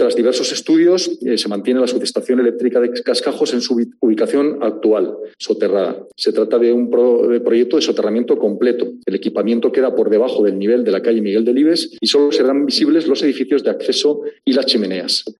Pablo Hermoso de Mendoza, alcalde de Logroño